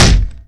boat_heavy_1.wav